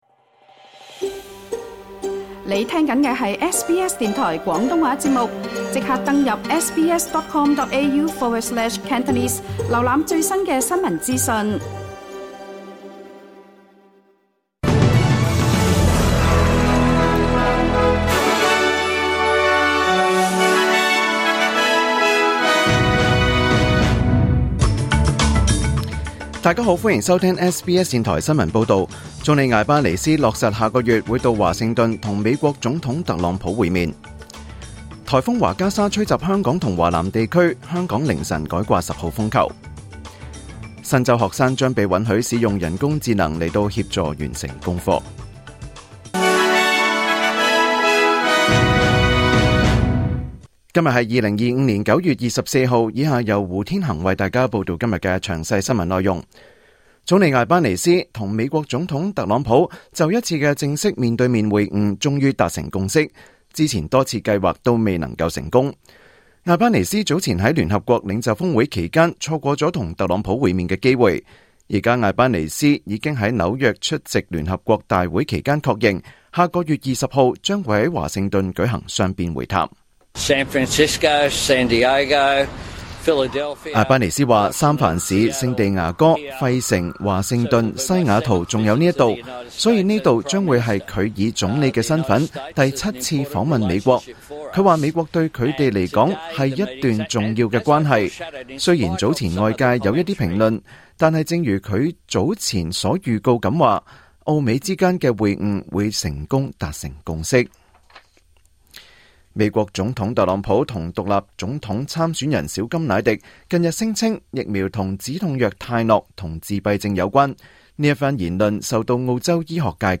2025 年 9 月 24 日 SBS 廣東話節目詳盡早晨新聞報道。